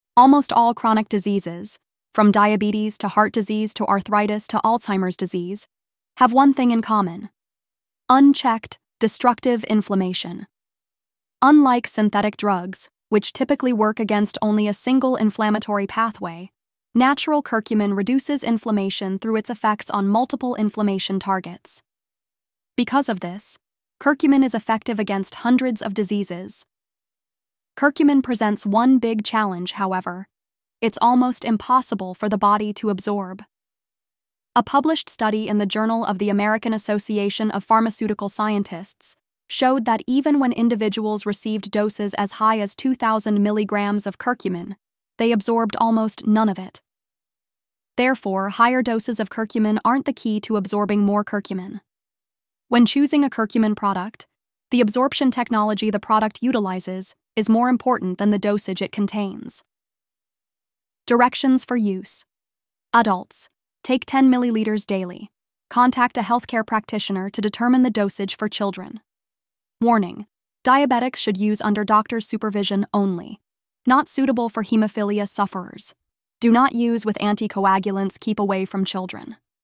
Narrated Product Information.
Oshun-Health-Curcumin-Boost-Voiceover.mp3